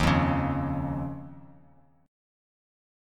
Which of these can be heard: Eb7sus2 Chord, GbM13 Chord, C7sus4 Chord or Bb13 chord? Eb7sus2 Chord